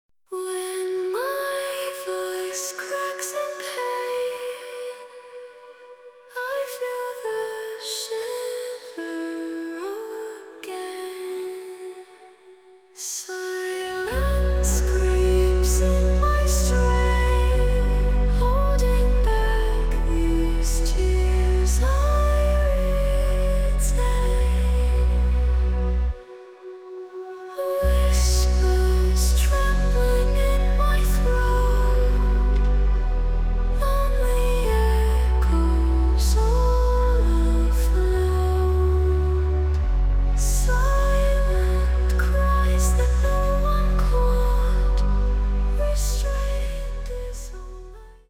Short version of the song, full version after purchase.
An incredible Pop song, creative and inspiring.